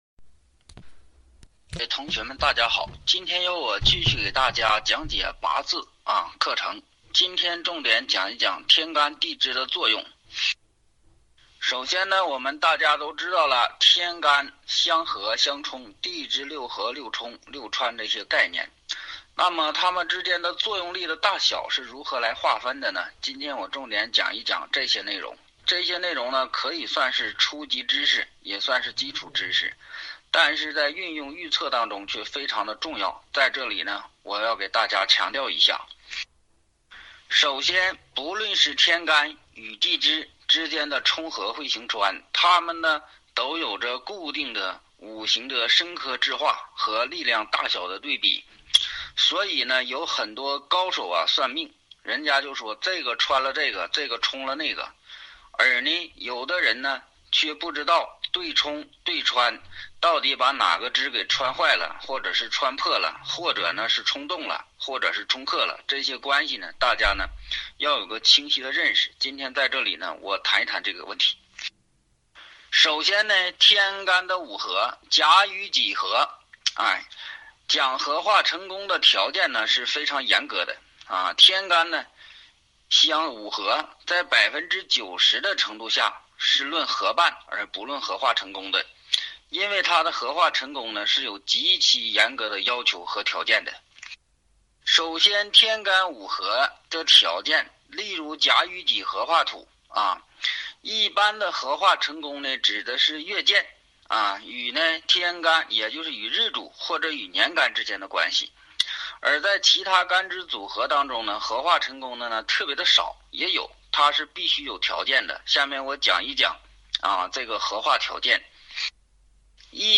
《八字命理中高级班》第二期课程46讲录音百度网盘分享